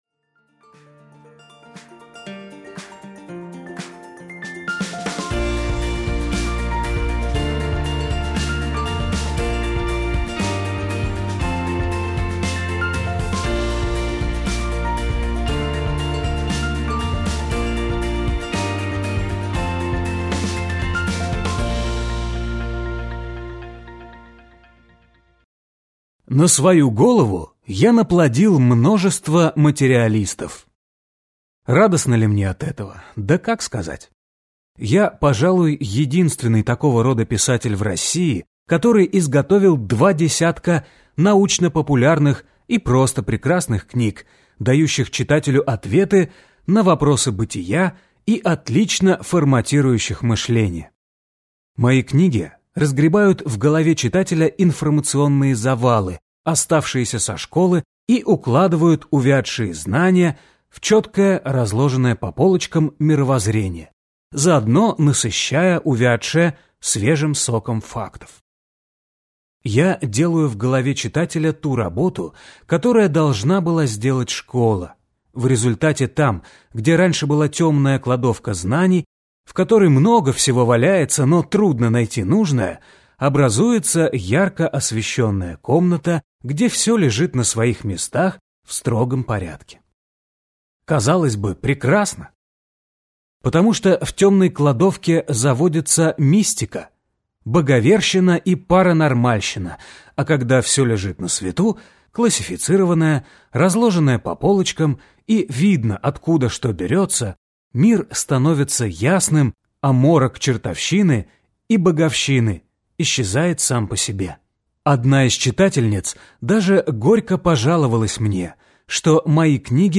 Аудиокнига Вечный sapiens. Главные тайны тела и бессмертия | Библиотека аудиокниг